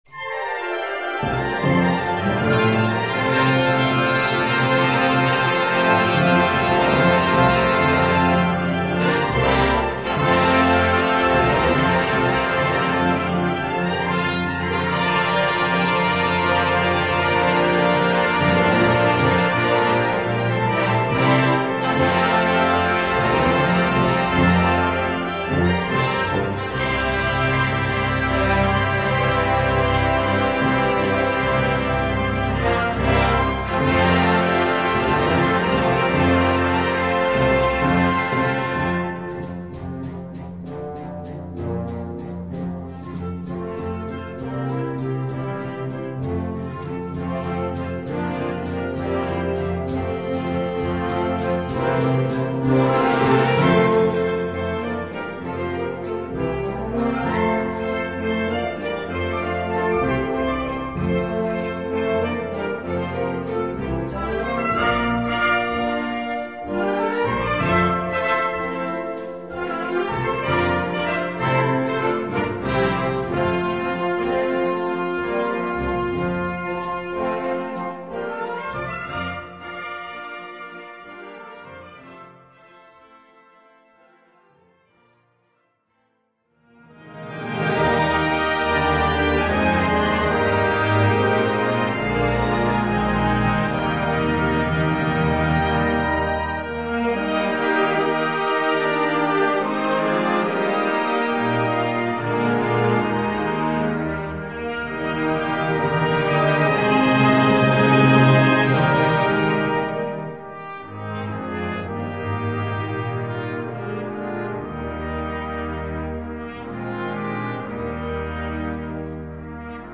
Dieses Konzertstück basiert auf baskischen Originalmelodien.
23 x 30,5 cm Besetzung: Blasorchester Zu hören auf